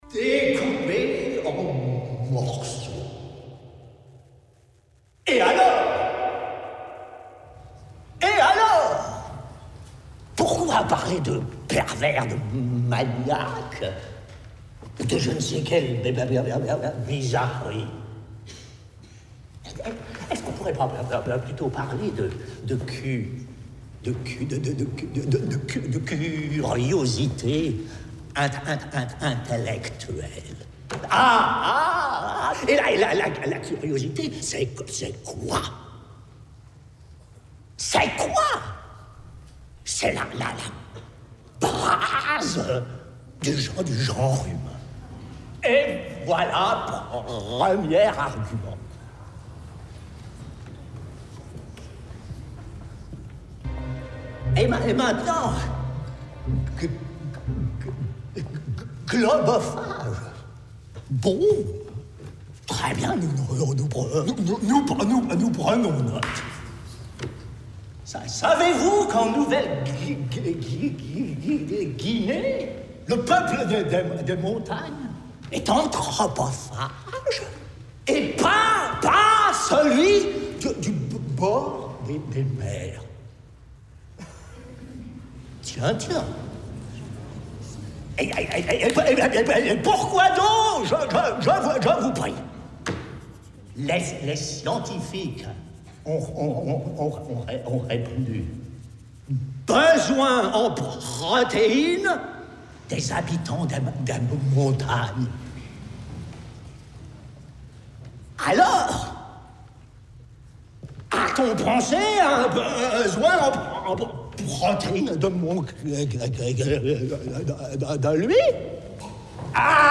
plaidoirie digne de ce nom :-D